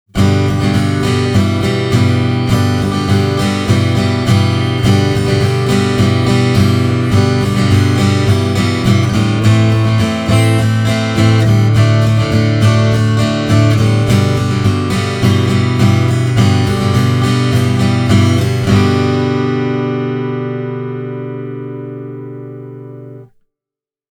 SRT-mallinnus muokkaa tallan alla olevan kontaktimikrofonin signaalia niin, että lopputulos kuulostaa studiomikrofonilla äänitetyltä.
Hyvän pianon lailla soundissa on tarkka atakki, täyteläinen keskialue sekä helisevä diskantti.
SRT-mallinuksen toiminta on aika vaikuttavaa, ja se poistaa signaalista kaikki pietsoon liityvät negatiiviset seikat. Ääni on selkeästi avoimempi ja luonnollisempi – SRT-virtuaalimikrofonivalinnasta riippuumatta.